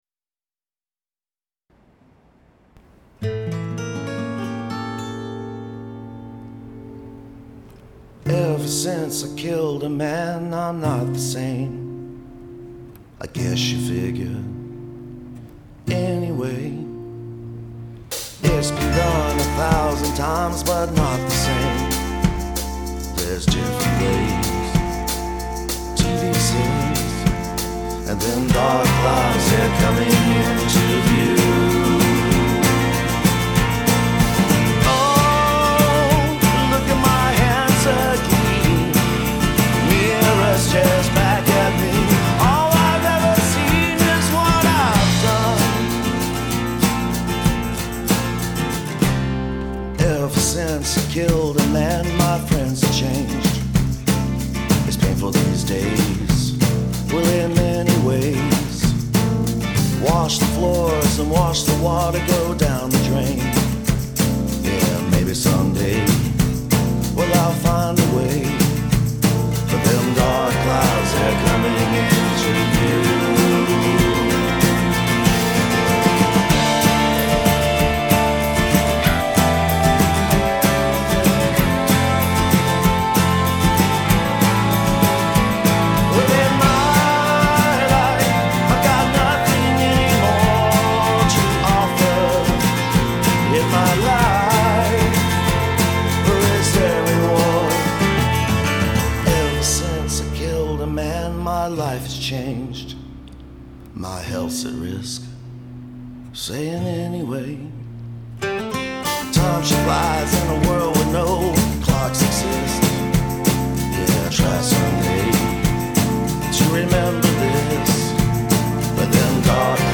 I've spent some time on this and i'm in a rut-the song deserves a better mix but I can't seem to get it there.